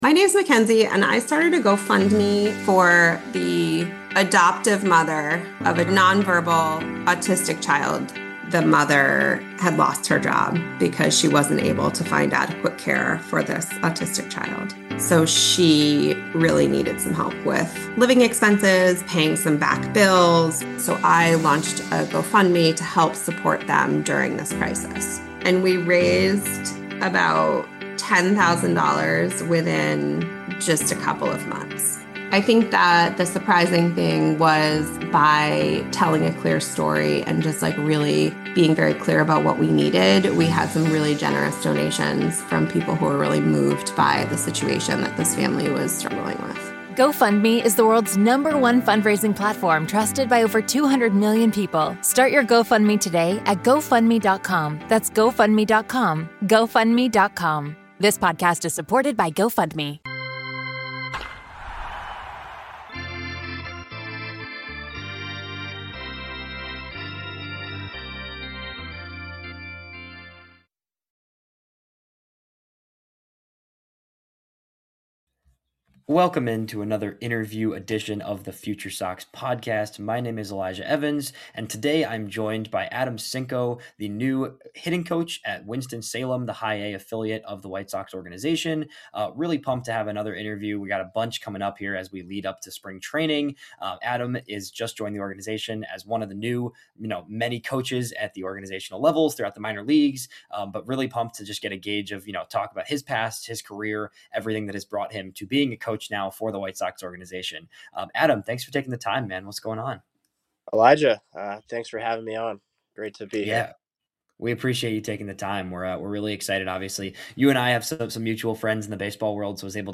FutureSox Interview Ft.